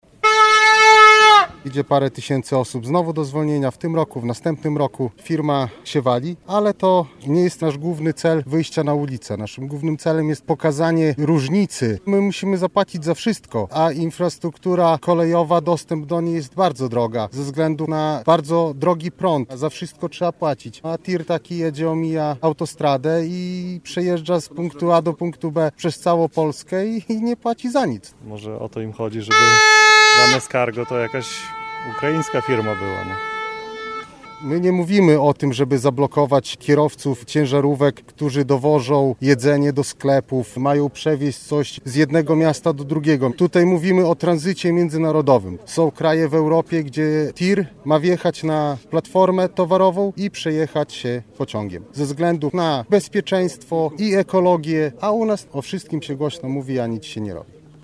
Drugi dzień protestów maszynistów - relacja